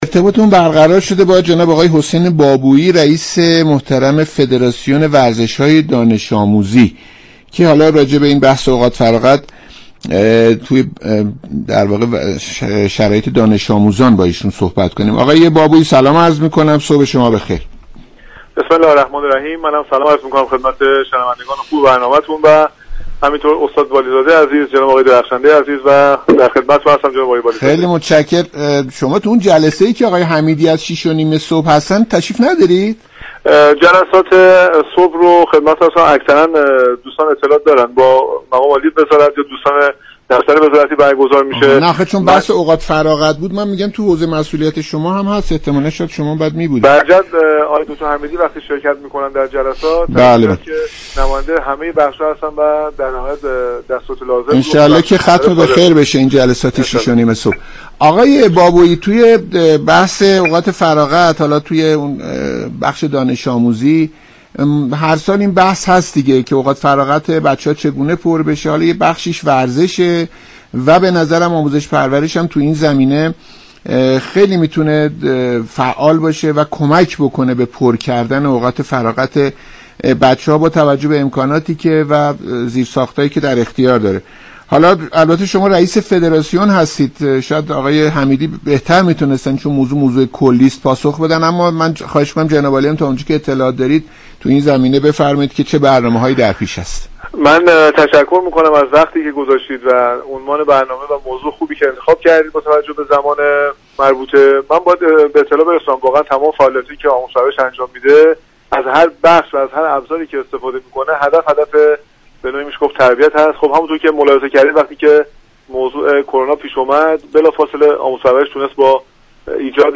برنامه «صبح و ورزش» شنبه 29 خرداد در گفتگو با حسین بابویی، رئیس فدراسیون ورزش های دانش آموزی به موضوع نقش ورزش در غنی كردن اوقات فراغت دانش آموزان پرداخت.